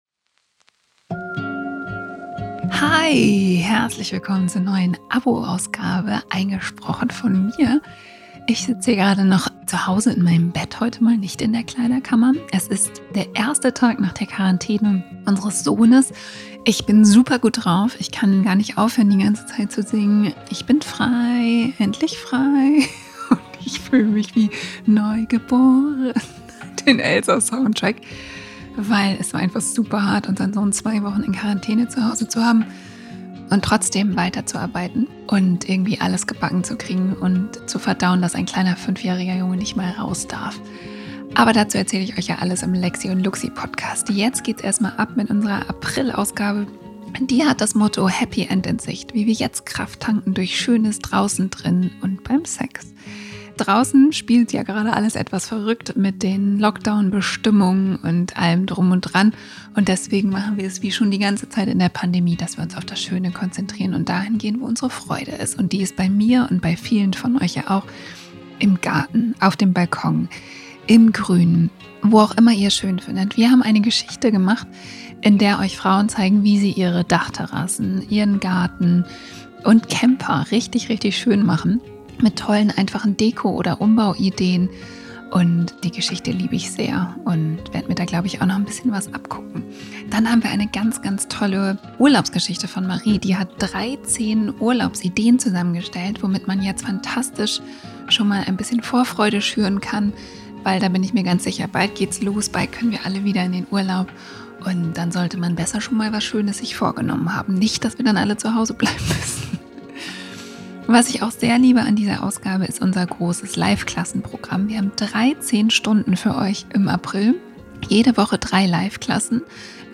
Mit richtig, richtig, richtig guter Laune habe ich die Abo-Gesamtausgabe dieses Mal eingesprochen.